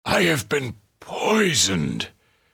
vs_falgerno_sick.wav